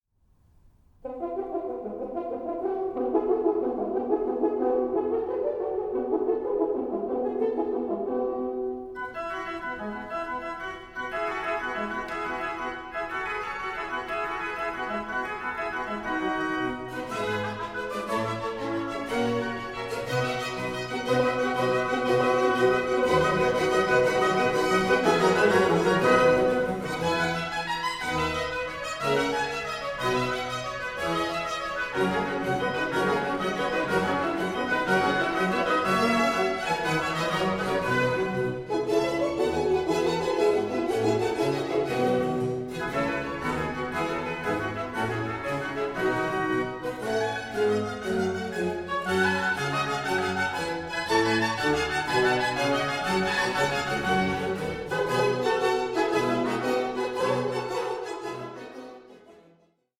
Organ Concerto No.16 in F major